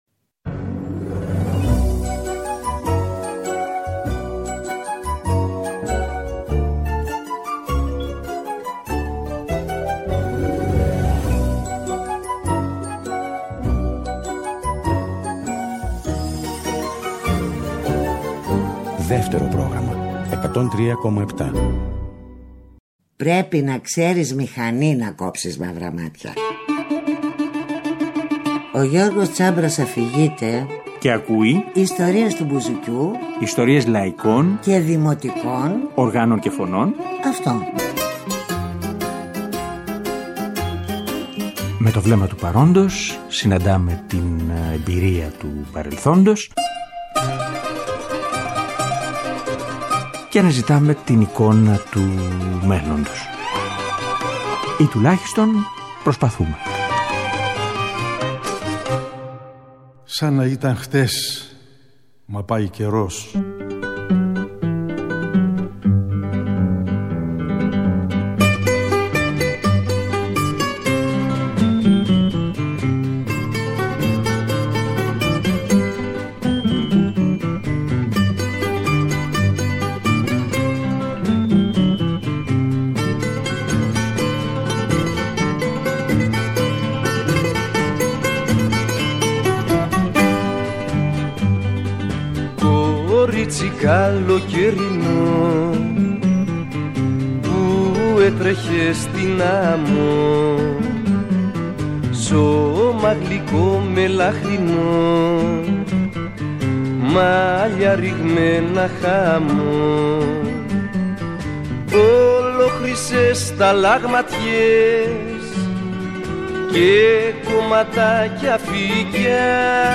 Μια από τις πιο συστηματικές καταγραφές, είναι αυτή στους «Λαϊκούς βάρδους» του Πάνου Γεραμάνη, το καλοκαίρι του 1992 – τα παιδιά που γεννήθηκαν τότε, είναι ήδη 31 χρόνων!
Και πιο πολύ αυτό που ήθελα, ήταν να ξανακουστεί η χροιά της φωνής του, ο τρόπος σκέψης και συζήτησης.
Και φυσικά, ανάμεσα σ’ αυτά και τα τραγούδια του, ίσως διαβάσουμε και κάποια μικρά αποσπάσματα από όσα γράφτηκαν τελευταία ή από όσα έγραψε ο ίδιος μετά!